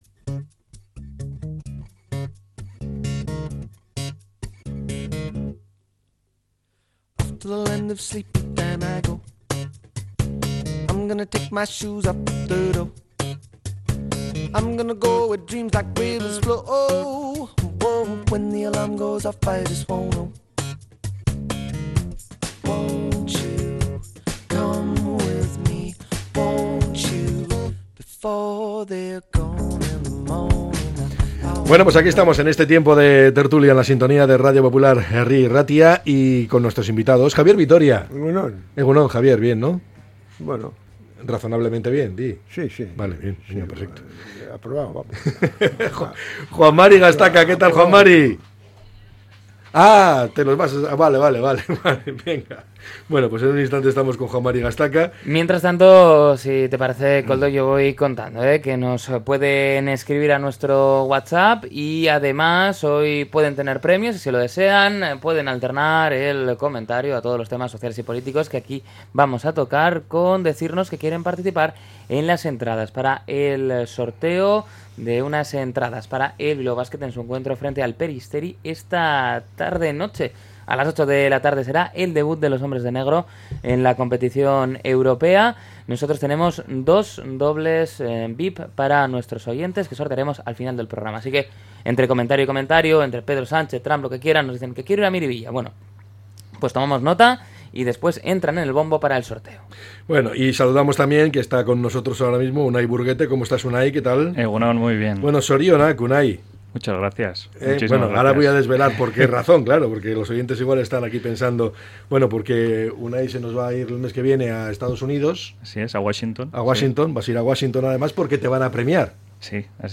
La tertulia 14-10-25.